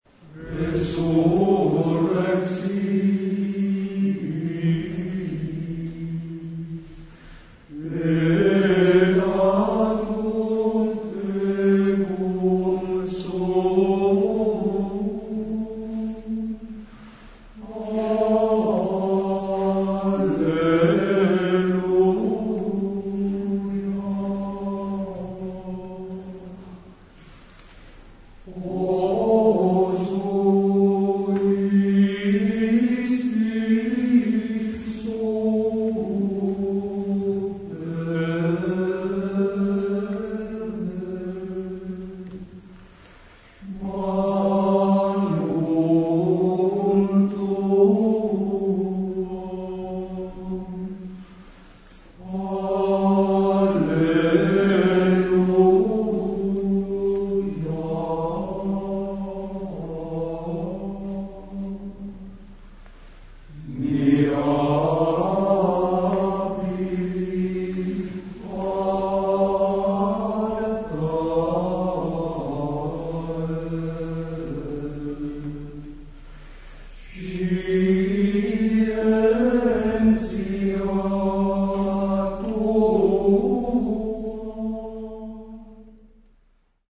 Coro Polifonico di Varzo -No-
Canto gregoriano
Resurrexi   Gregoriano.mp3